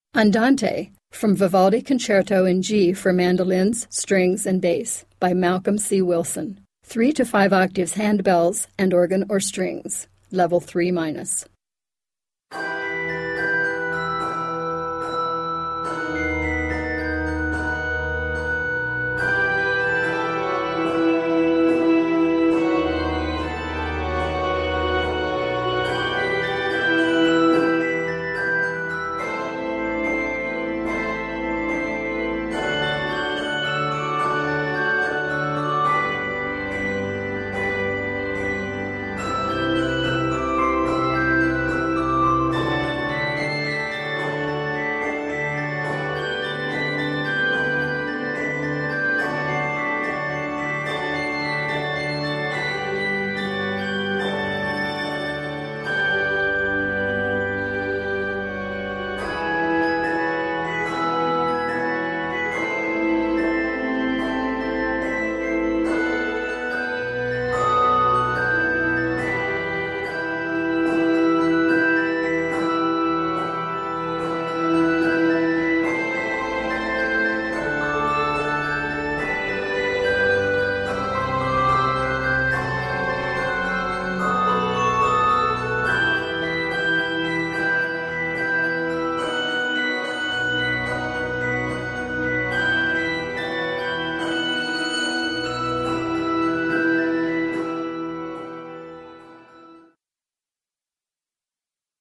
Scored in e minor, it is 36 measures.